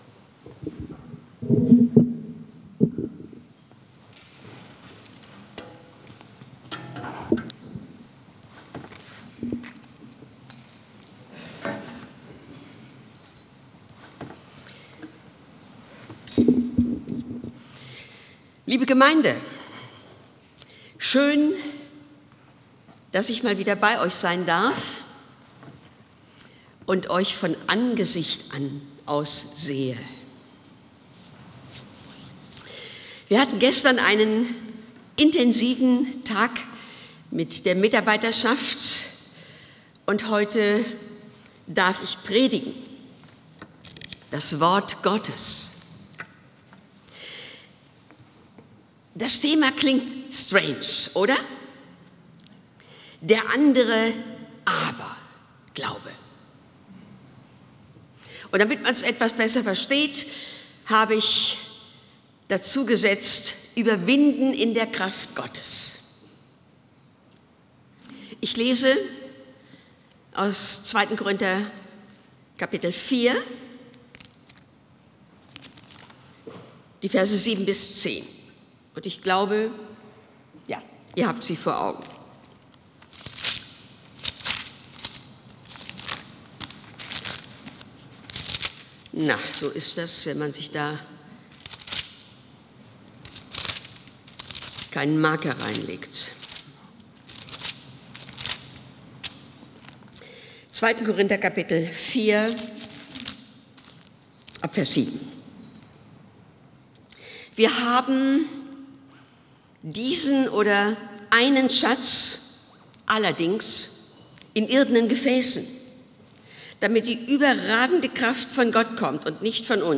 Predigt Der andere Aber-Glaube